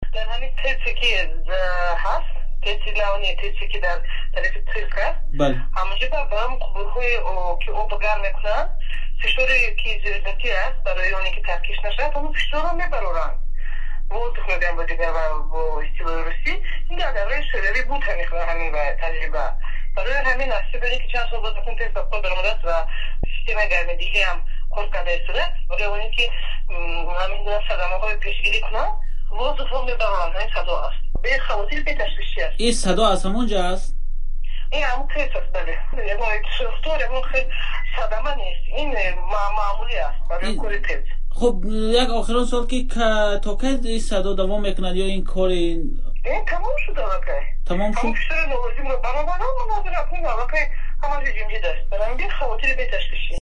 дар тамоси телефонӣ ҳодисаро чунин шарҳ дод
Шарҳи